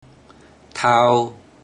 Click each Romanised Teochew word or phrase to listen to how the Teochew word or phrase is pronounced.
thao1